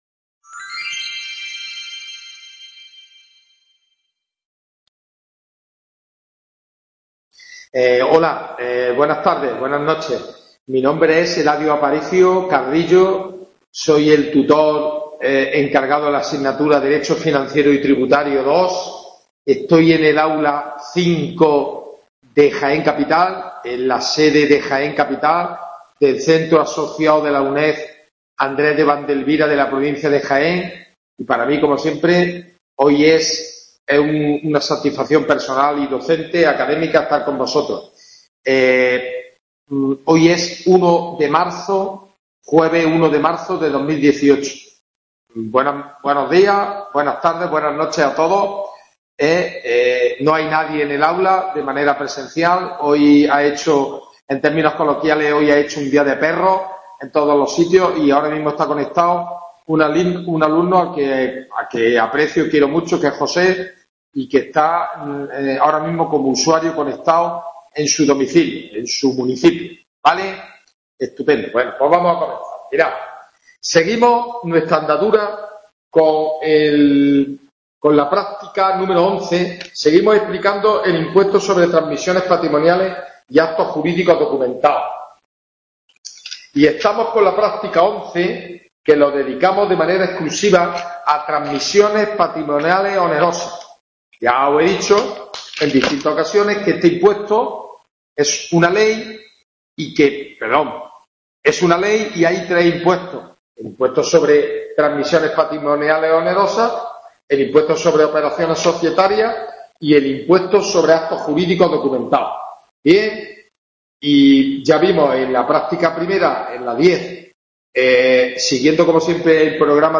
WEB CONFERENCIA "Dº F y T II" (1-3-2018).